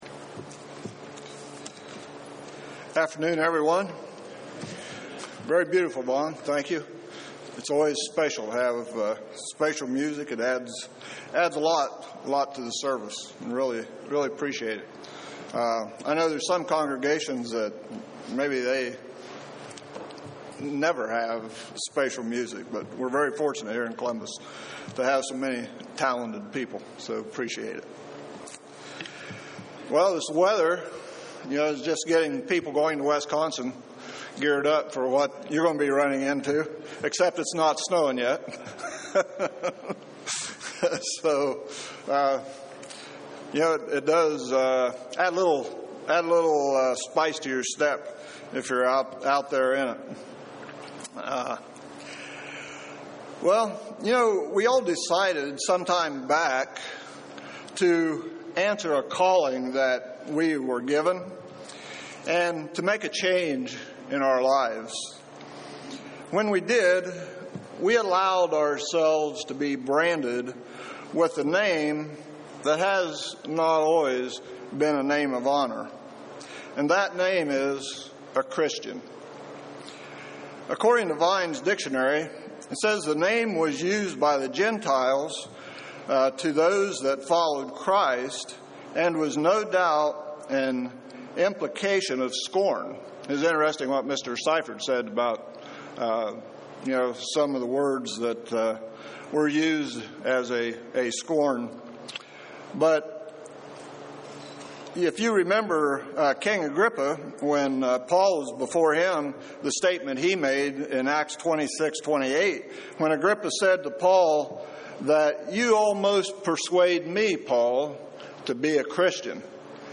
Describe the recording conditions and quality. Given in Columbus, OH